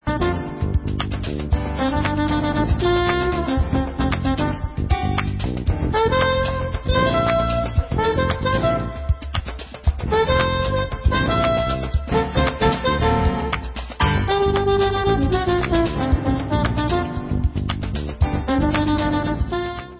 sledovat novinky v oddělení Jazz